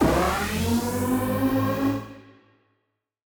Index of /musicradar/future-rave-samples/Siren-Horn Type Hits/Ramp Up
FR_SirHornA[up]-A.wav